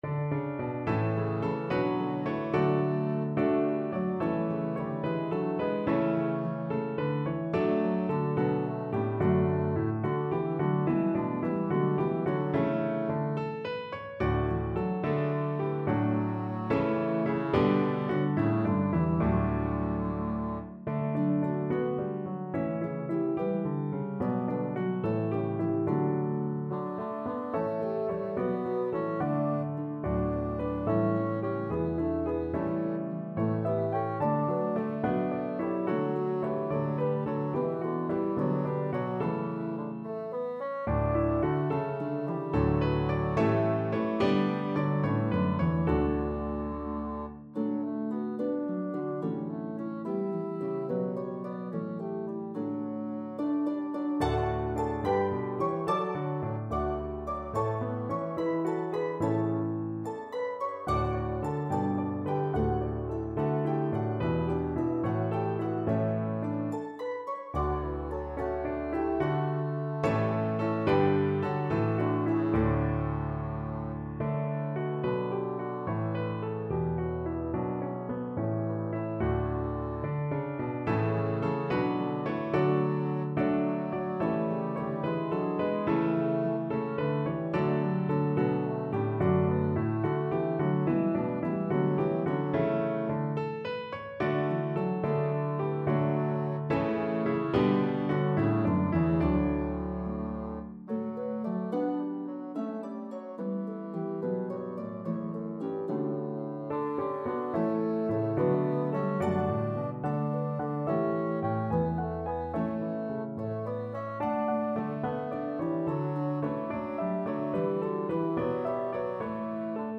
It is now both an Advent Hymn and Christmas Carol.